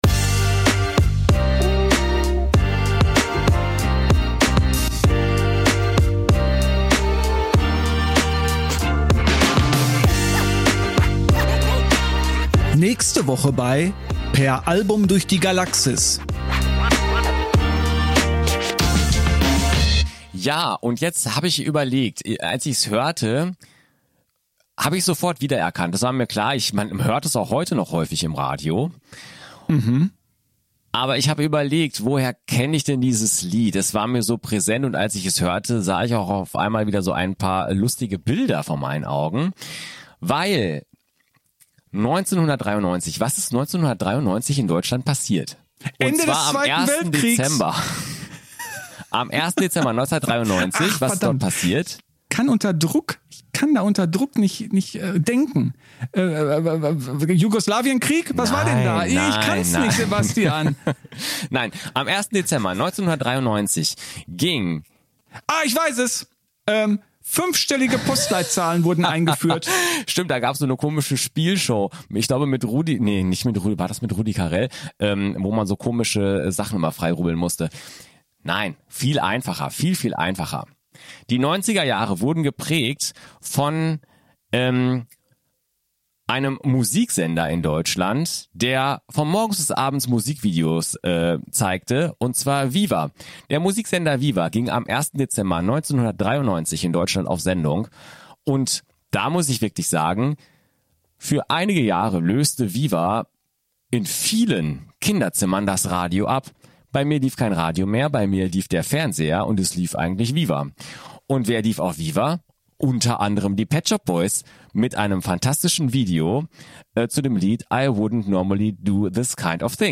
Intro & Outro